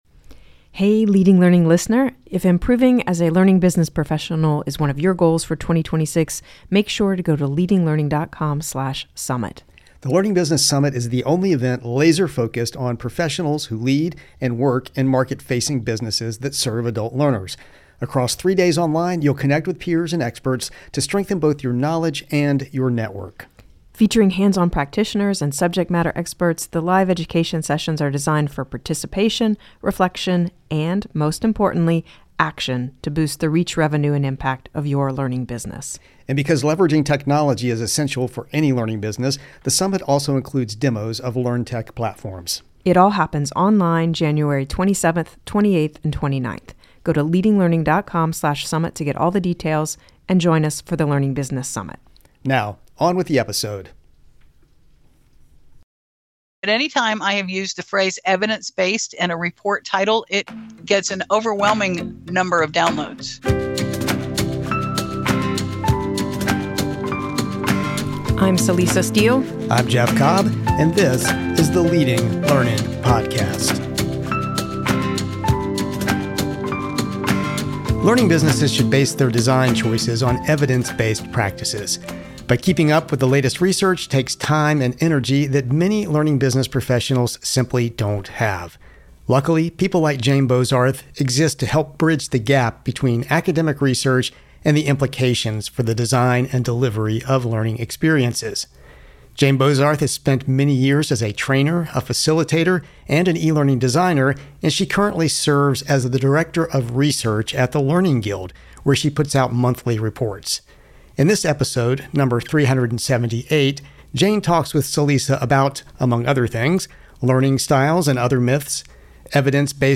Leading Learning Podcast interviewee